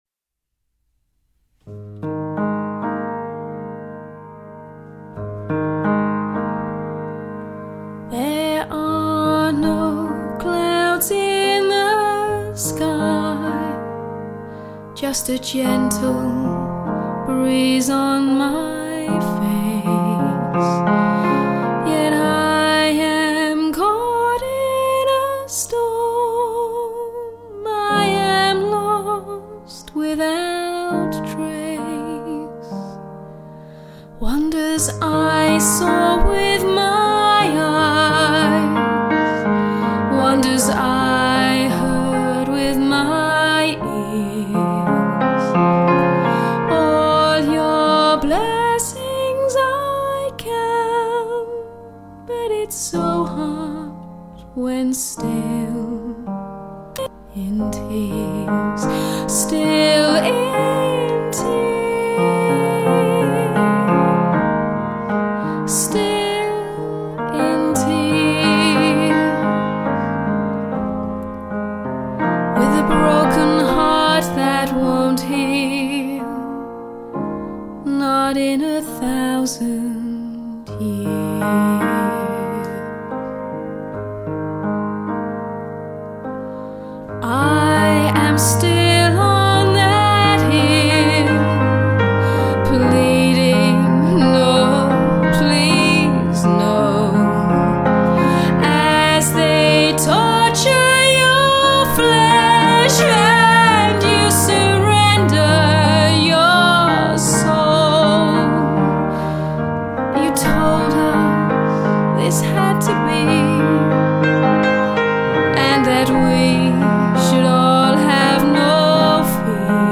vocals and piano
cello